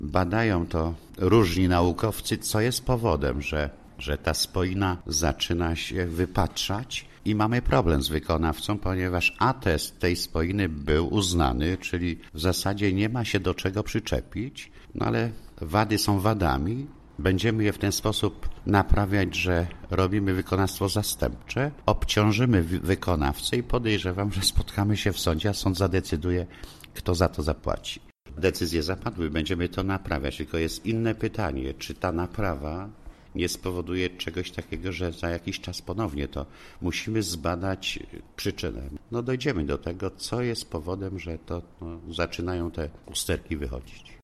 Jak zauważyli użytkownicy żnińskiej starówki po rewitalizacji wystąpiły ubytki i wybrzuszenia w nawierzchni, przyczyna nie jest jeszcze znana, jednak burmistrz Żnina Leszek Jakubowski zapewnia, że decyzje o naprawie zapadły.
2014-burmistrz-rynek2.mp3